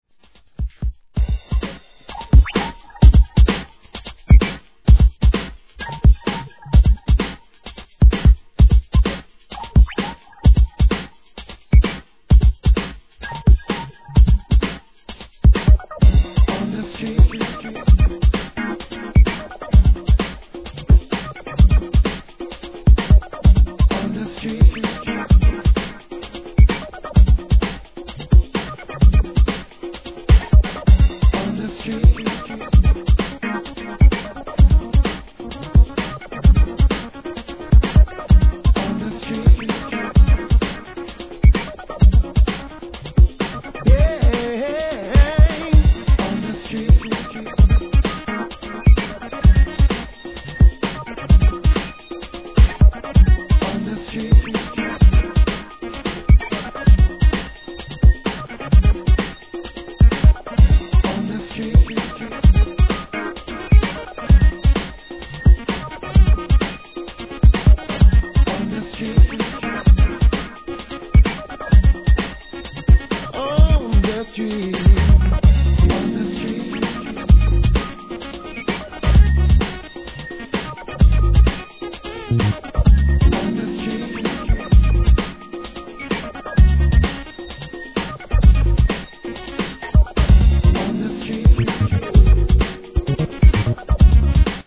HIP HOP/R&B
HOUSE 〜 R&Bとクロスオーバーする方へ！